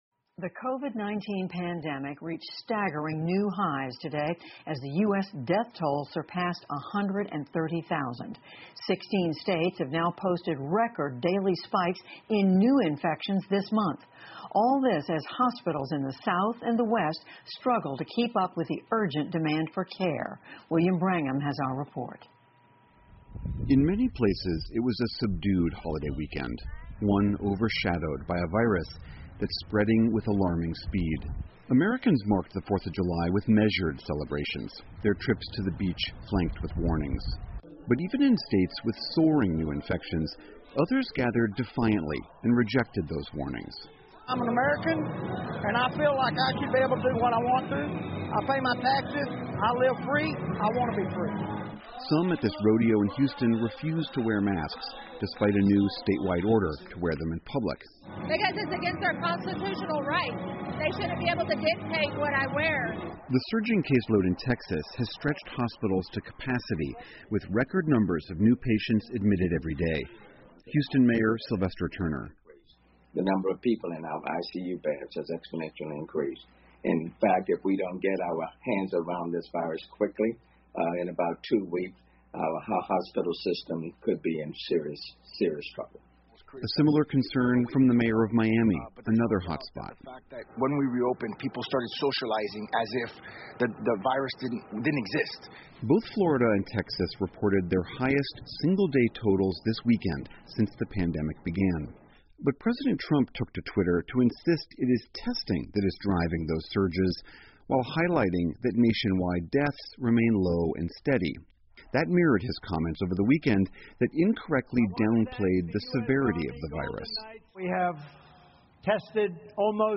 在线英语听力室PBS高端访谈:特朗普称美国新冠死亡人数较低?的听力文件下载,本节目提供PBS高端访谈健康系列相关资料,内容包括访谈音频和文本字幕。